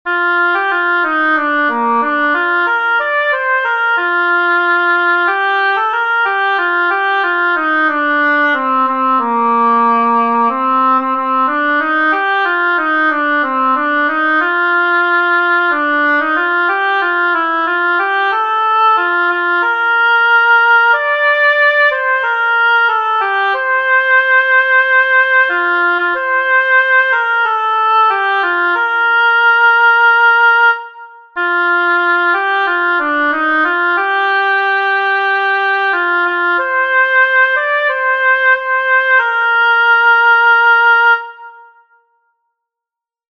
【校歌】　（昭和29年制定）
校歌.pdf  　メロディが流れます⇒
校歌.mp3